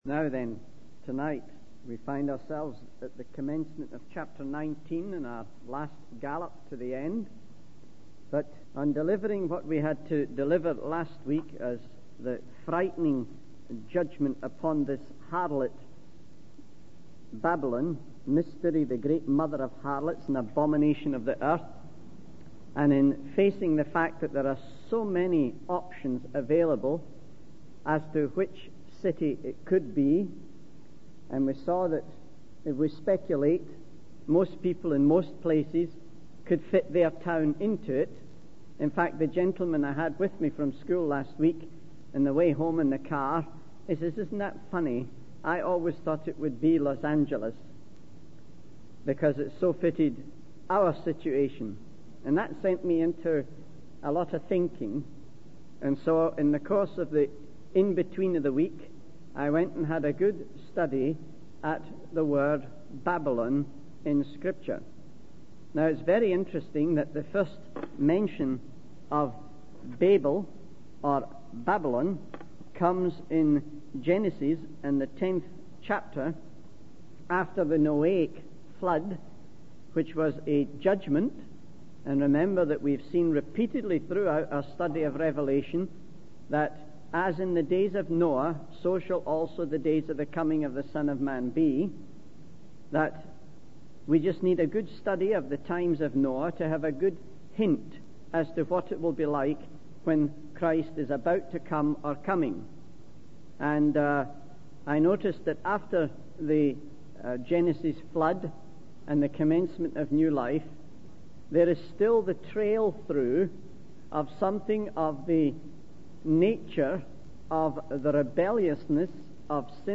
The sermon then shifted to discussing the false peacemaker in the Middle East and the ultimate judgment and rule of Christ, as described in the book of Revelation.